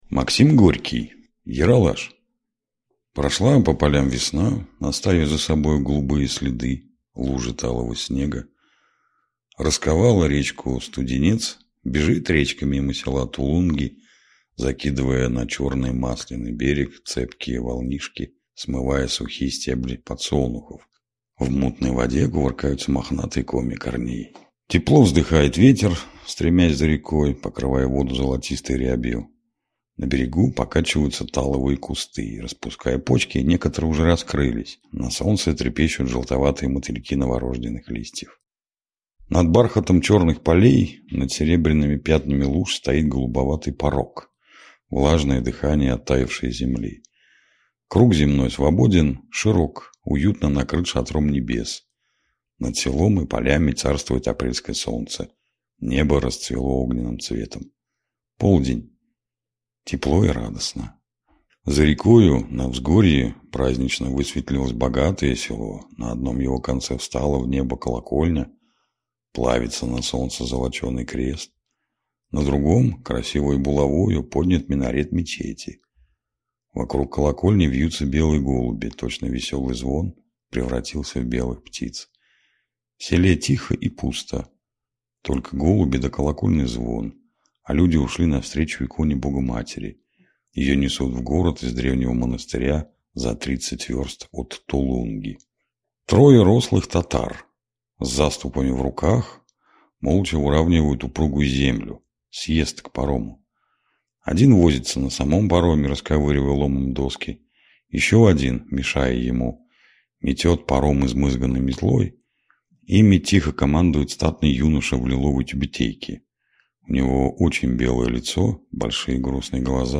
Аудиокнига Ералаш | Библиотека аудиокниг